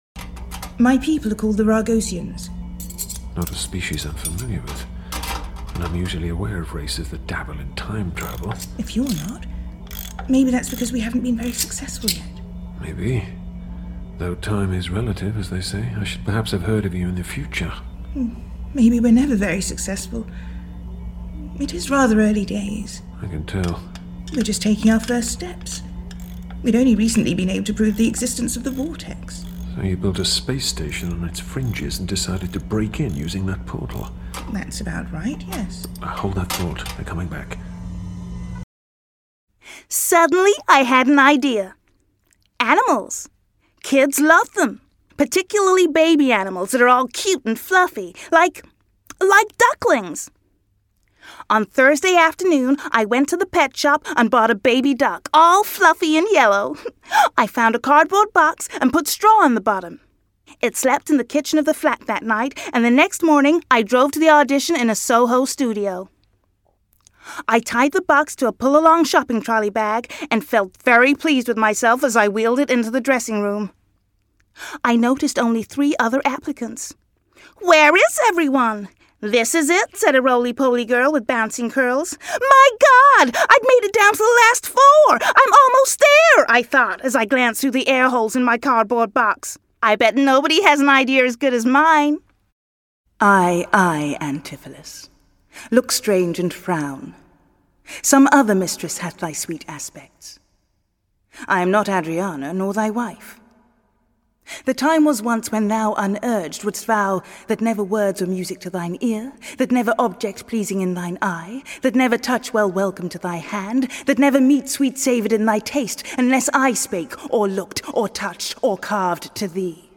Native Accent: RP Characteristics: Warm and Distinct Age
Character Reel